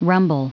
Prononciation du mot rumble en anglais (fichier audio)
Prononciation du mot : rumble